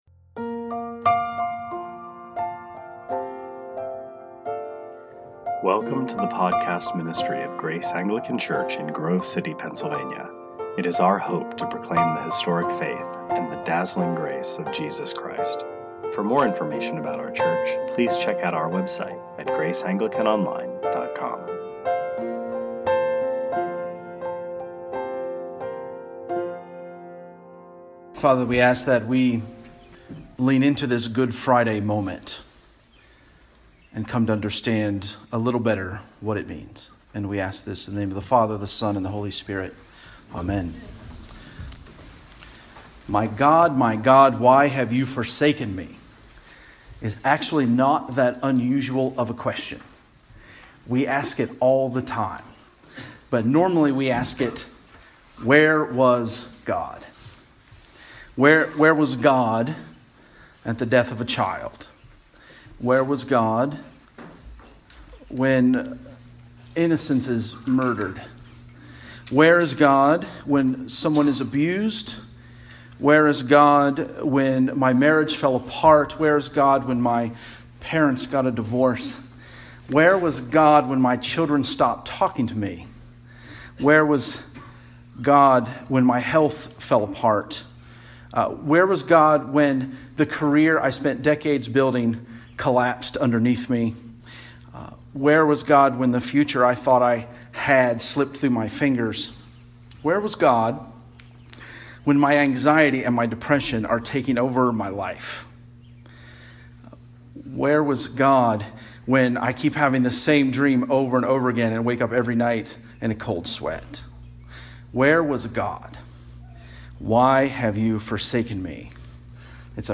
2018 Sermons Where are You?